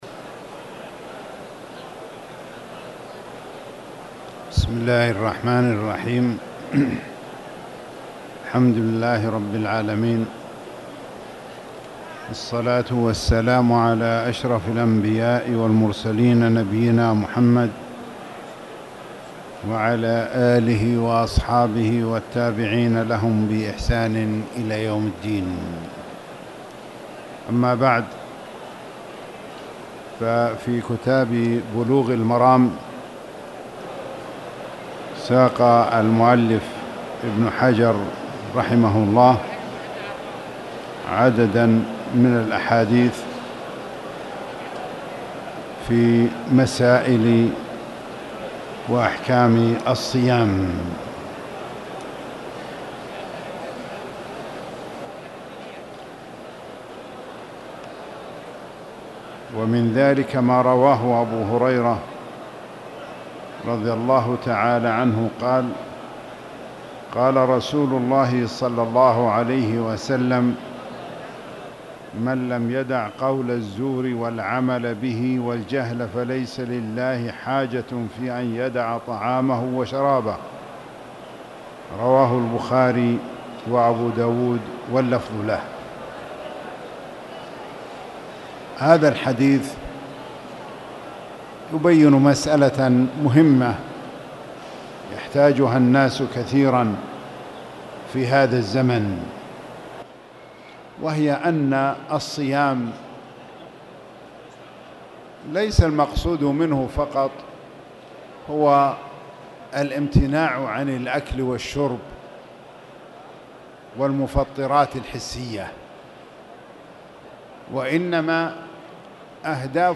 تاريخ النشر ١٠ رمضان ١٤٣٧ هـ المكان: المسجد الحرام الشيخ